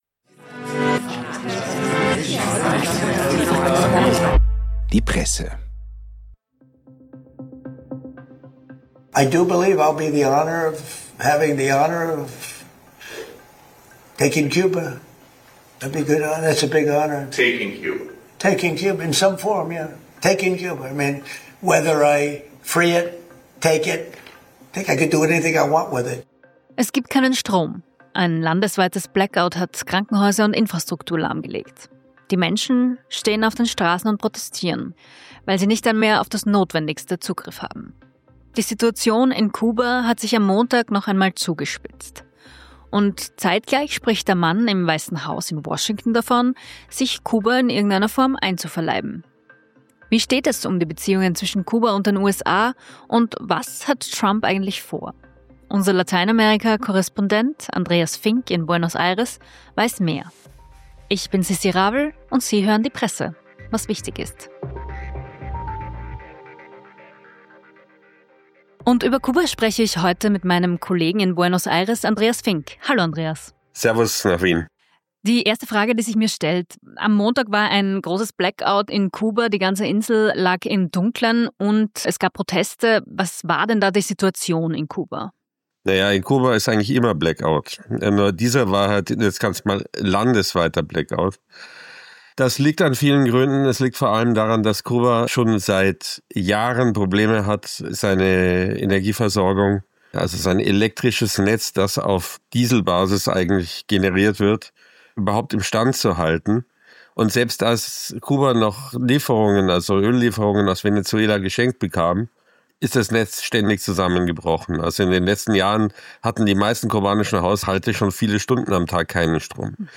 Donald Trump wolle sich Kuba „nehmen“ und damit könne er „machen, was er will“, hieß es am Montag. Korrespondent